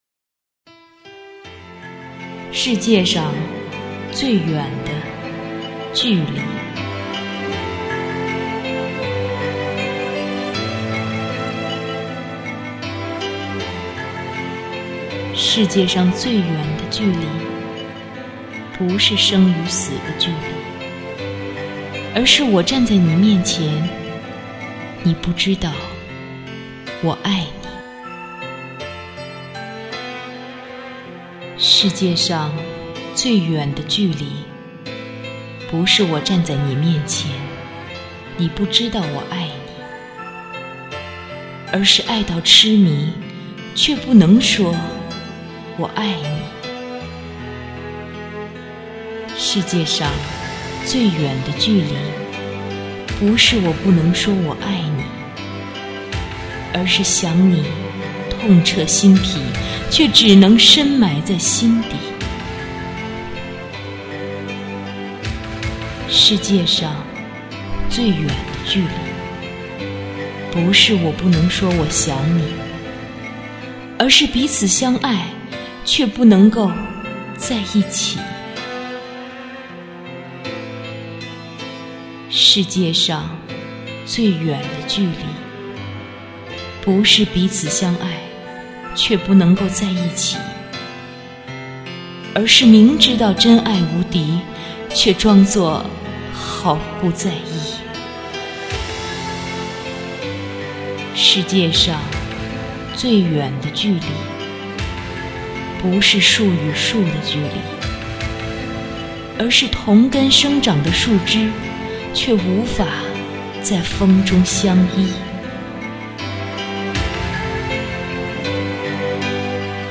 关于爱与情的诗——世界上最远的距离，网上流传的这首诗借用泰戈尔其名，但内容其实是美丽异常的。不同人用不同的心情和语境进行了不同的演绎。